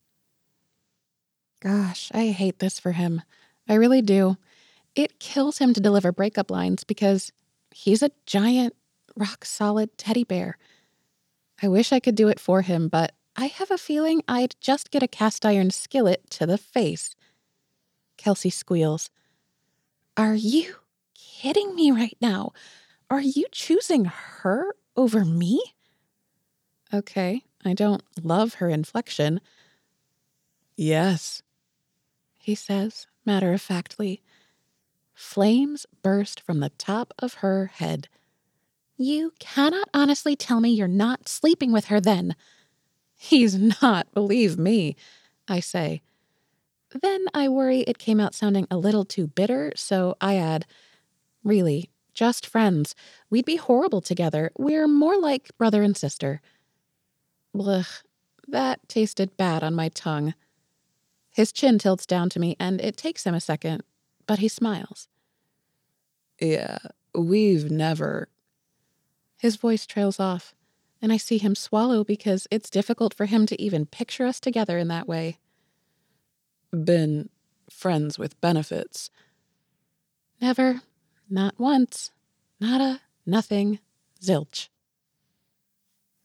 Rom Com 1st Person: Are you choosing her over me? (2F, 1M)
romcom-sample.wav